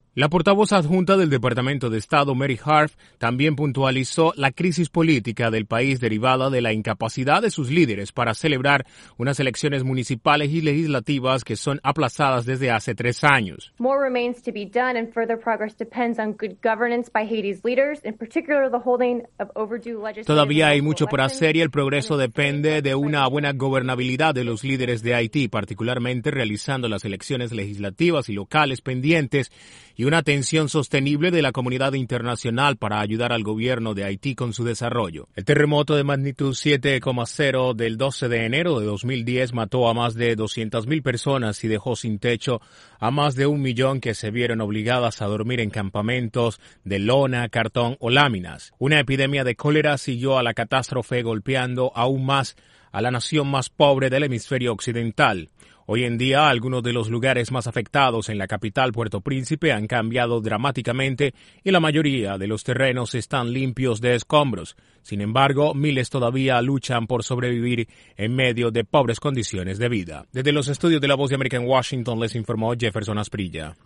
El gobierno de Estados Unidos reconoce que todavía hay mucho por hacer tras 5 años del devastador terremoto que sacudió Haití. Desde la Voz de América en Washington informa